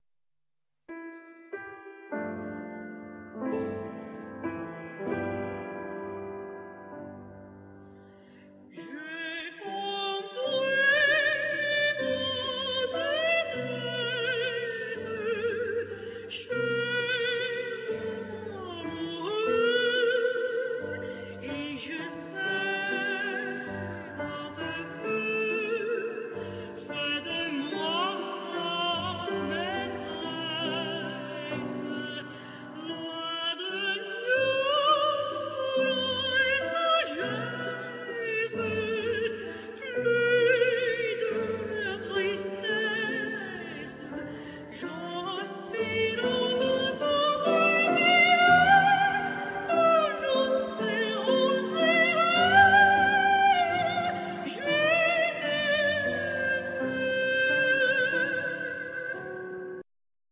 Vocal
Piano